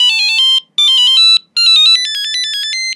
Tono de teléfono móvil 16